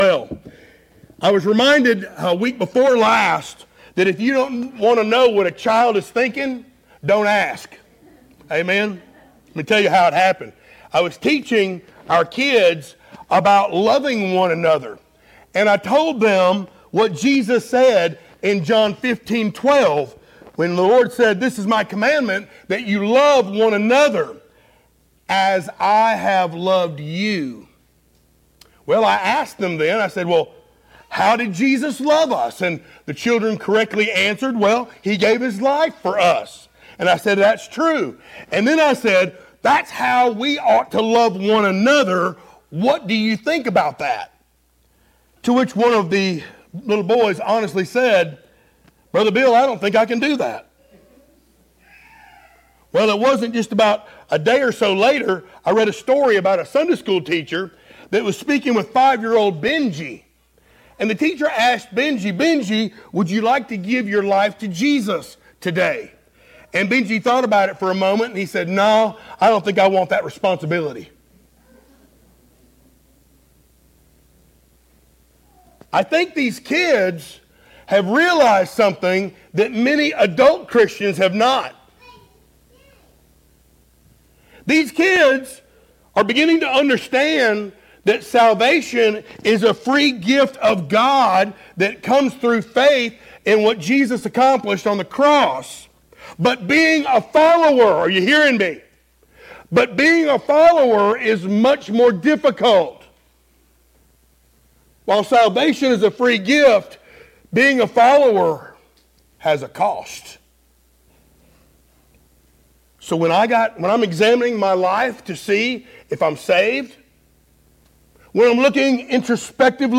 Series: sermons